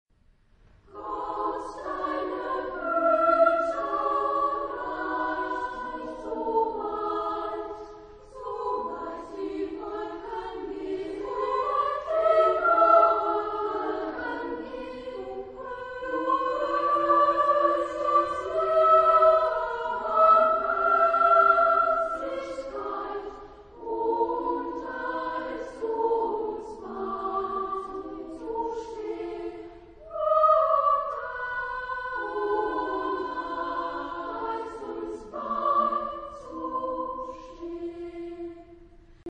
Epoque: 19th century
Genre-Style-Form: Motet ; Sacred
Type of Choir: SSA  (3 women voices )
Tonality: E major
Discographic ref. : 7. Deutscher Chorwettbewerb 2006 Kiel